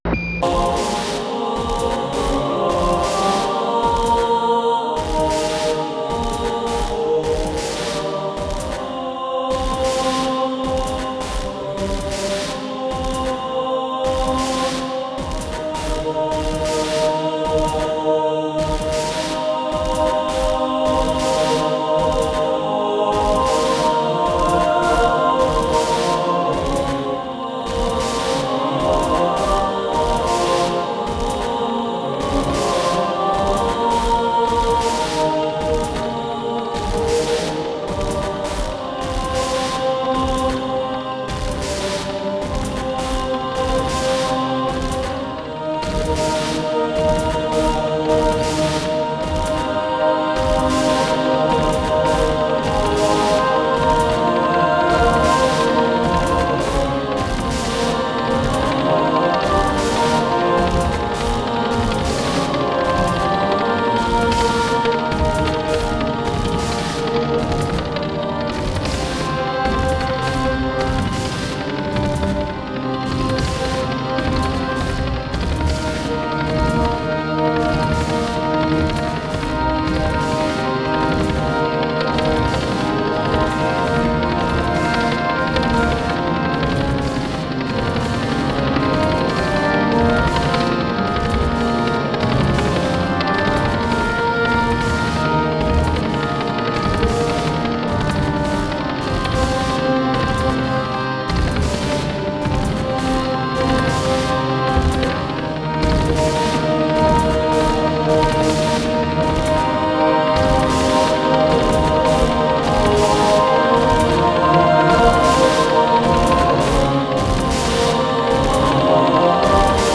祈り、
合唱、
音声モーフィング、
オルガン、
ハードコア・ビート、
不整動パンニング、
非実存ギターによるパーカッシブ・タッピング、
宇宙船からの通信、
先端的テクノ・マニアにもお薦めのアルバム。